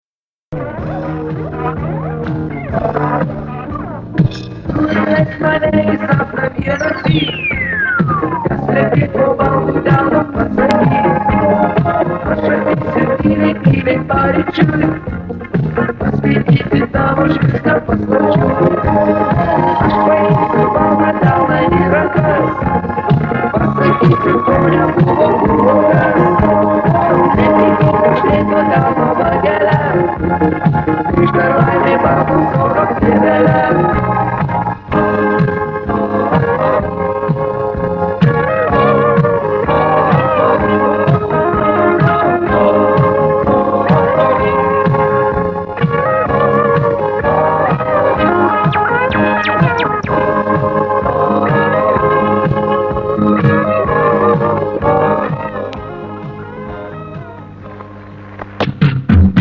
ТВ версия